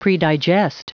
Prononciation du mot predigest en anglais (fichier audio)
Prononciation du mot : predigest